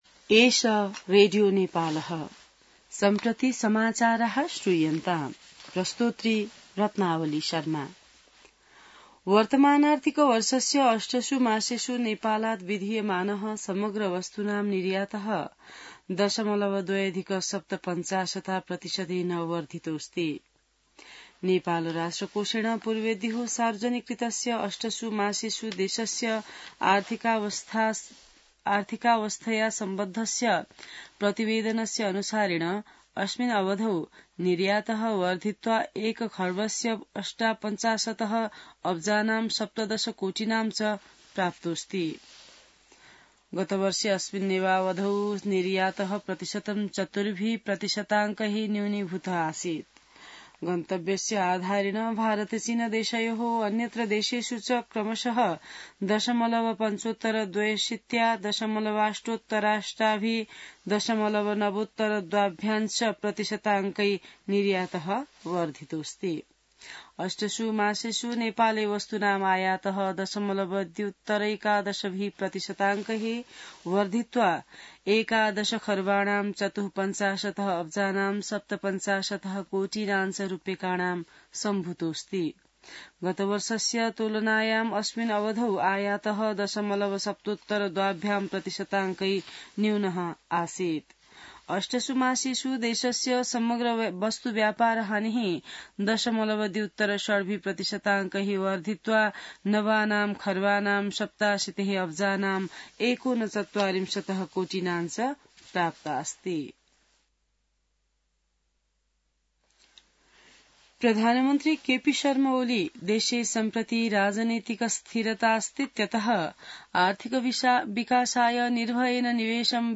संस्कृत समाचार : २८ चैत , २०८१